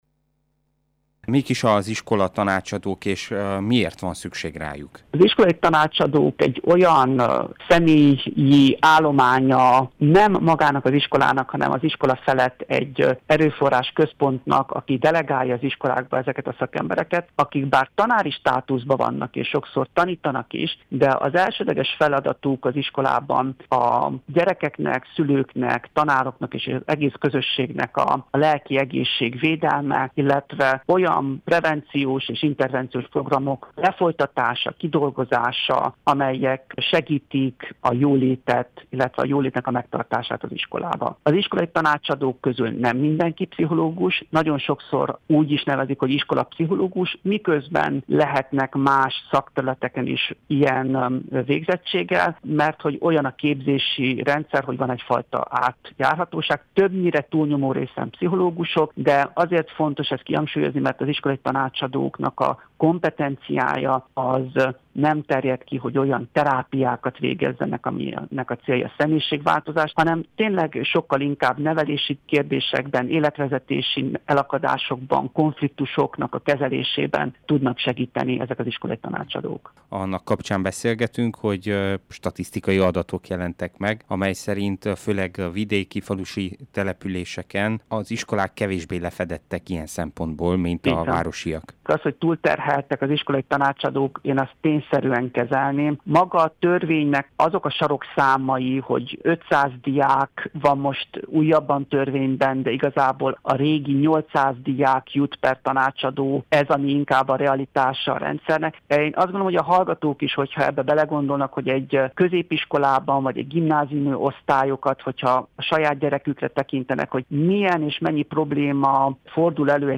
A riporter